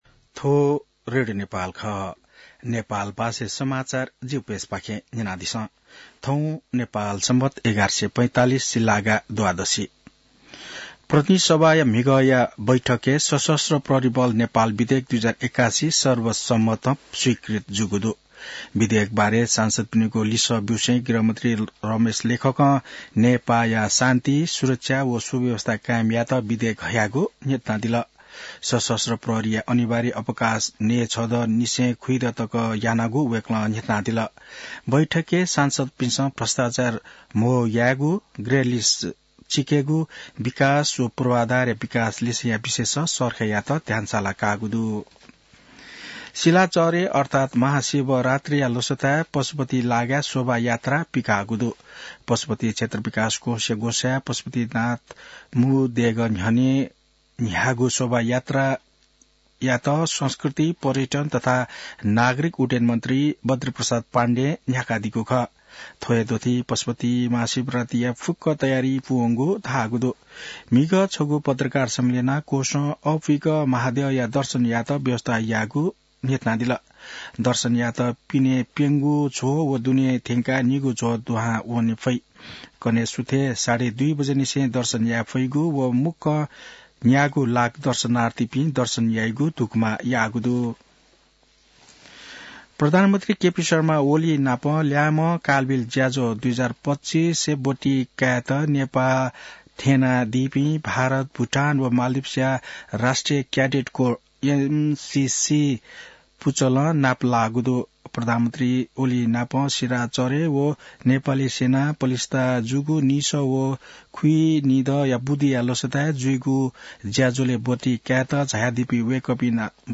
नेपाल भाषामा समाचार : १४ फागुन , २०८१